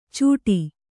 ♪ cūṭi